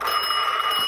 Alarm Bell.wav